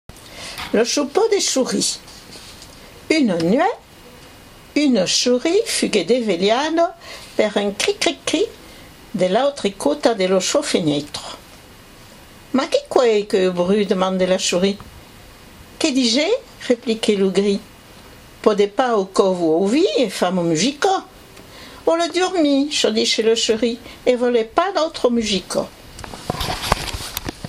La Dictada daus Pitits